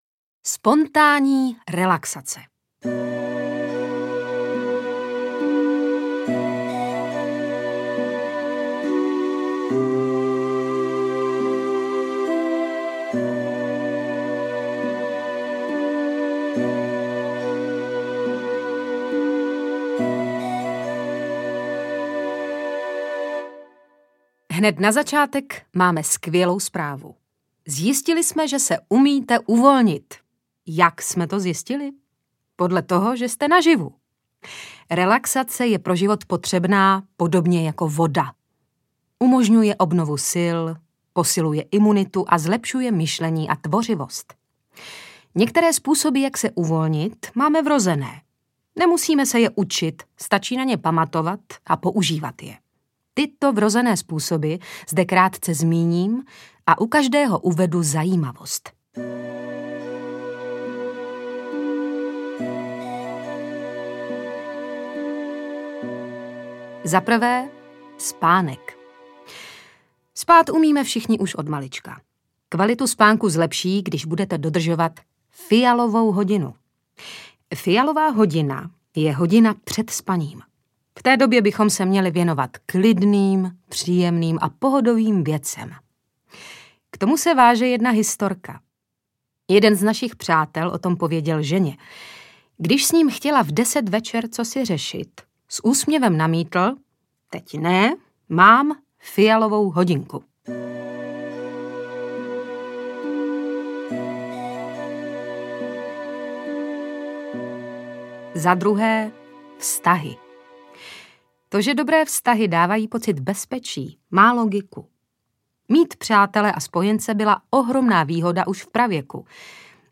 Kudy do pohody audiokniha
Ukázka z knihy